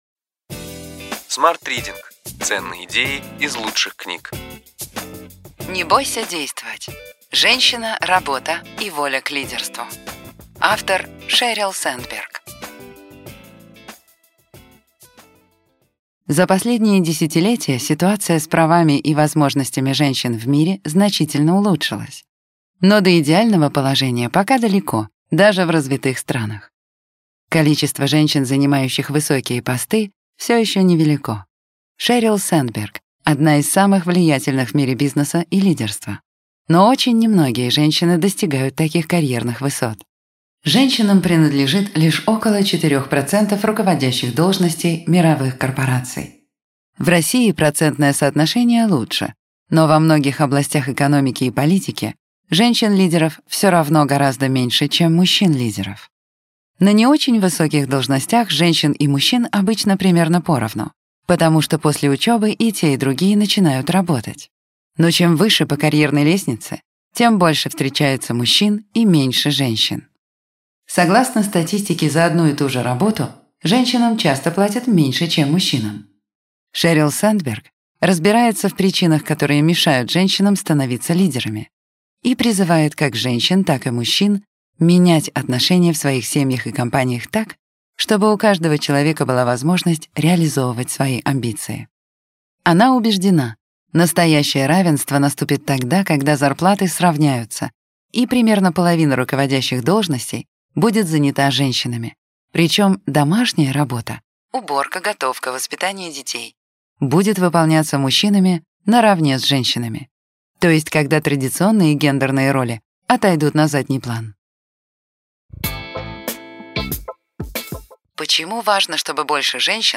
Аудиокнига Ключевые идеи книги: Не бойся действовать. Женщина, работа и воля к лидерству.